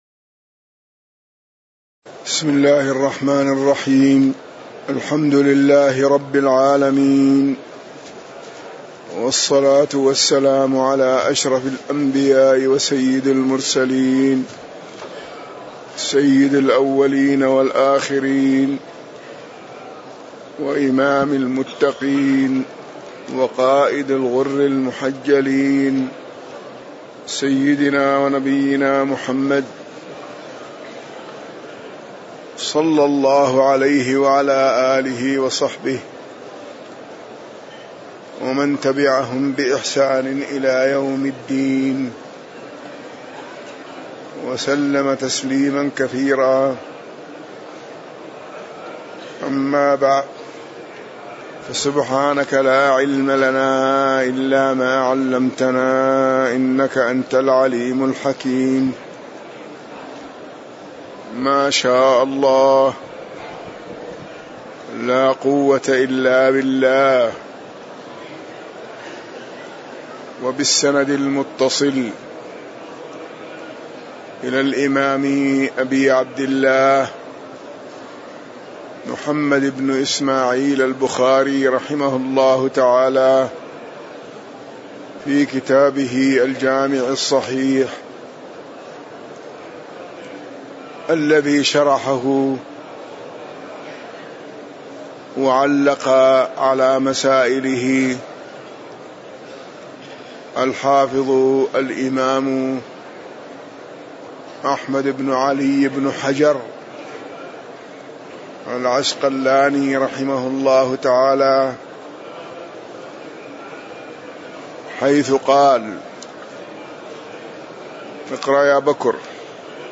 تاريخ النشر ٦ محرم ١٤٣٩ هـ المكان: المسجد النبوي الشيخ